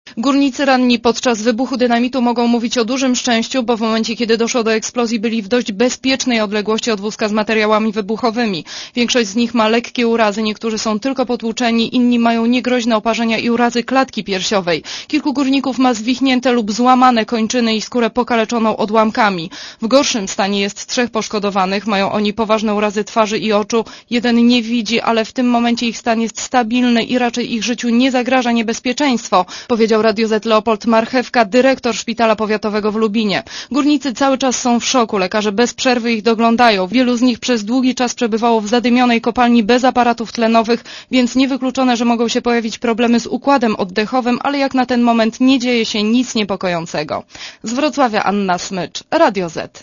Posłuchaj relacji reporterki Radia Zet (193 KB)